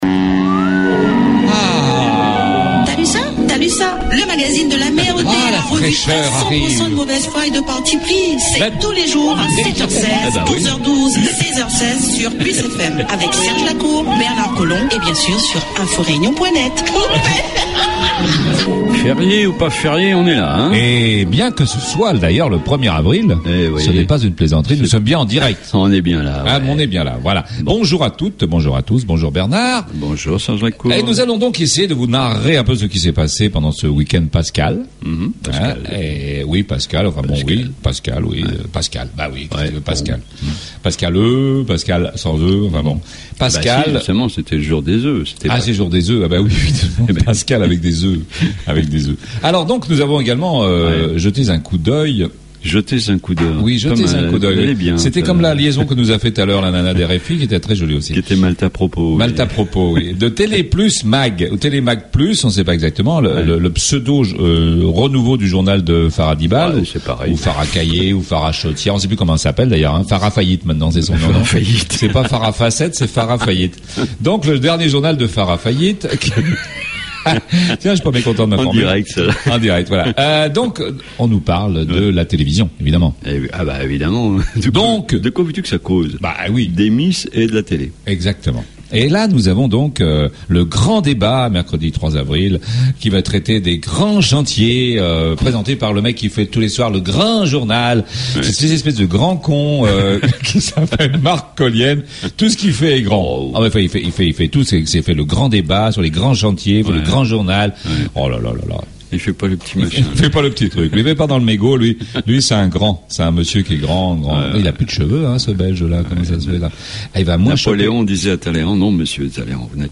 THALUSSA le magazine de l'amer, du lundi 1er avil 2013 REVUE DE PRESSE Lundi 1 Avril 2013 La Revue de Presse politiquement incorrecte la mieux informée, la plus décalée, la plus drôle, la moins sérieuse et surtout la plus écoutée sur PLUS FM 100.6 sur le Nord de l'ile et 90.4 dans l'ouest...